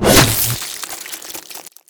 pslash.wav